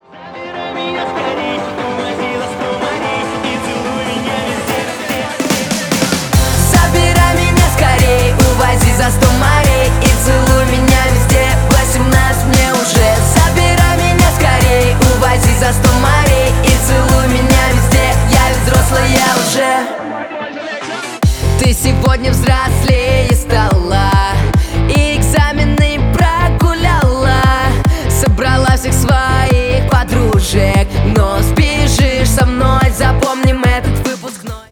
Поп Музыка
клубные # кавер